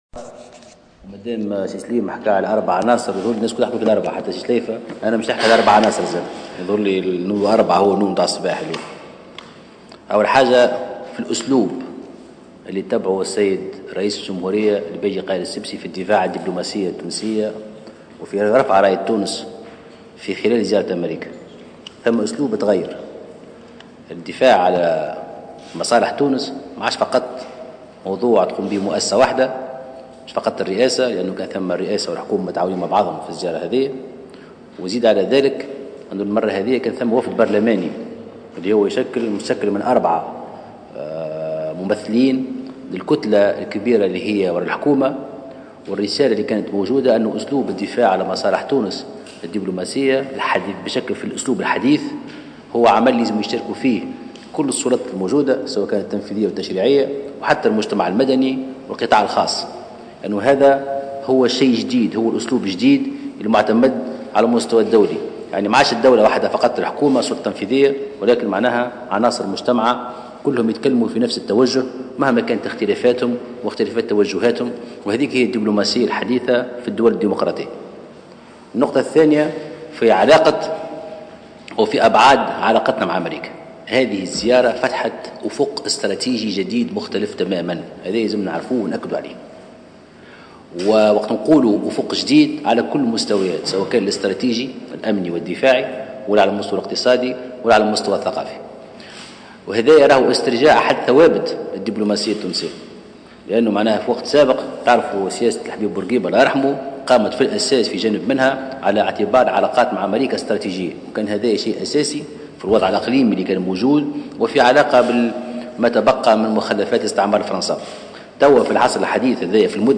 قال مستشار رئيس الجمهورية محسن مرزوق في ندوة صحفية عقدت اليوم، إن زيارة الباجي قائد السبسي للولايات المتحدة الامريكية فتحت أفقا استراتجيا جديدا على شتى المستويات الأمنية والثقافية والإقتصادية إضافة إلى كونها ساهمت في استرجاع أحد ثوابت الديبلوماسية التونسية.